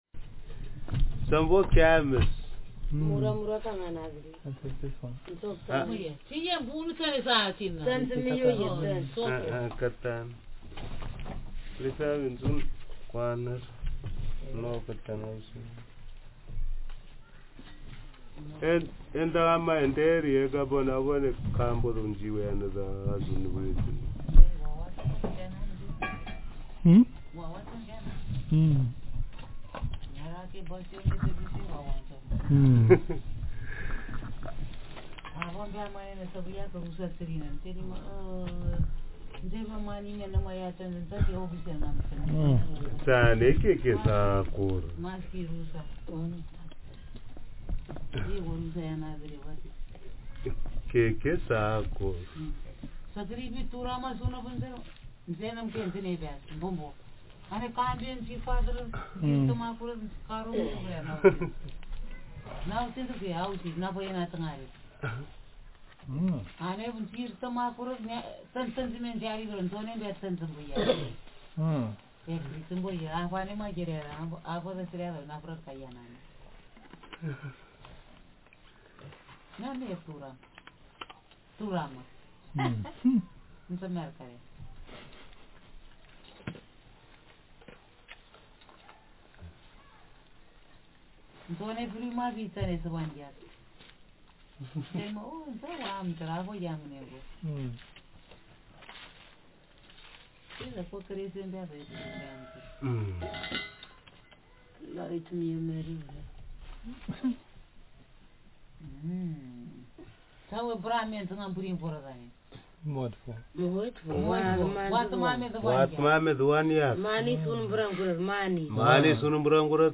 Speaker sex f/m Text genre conversation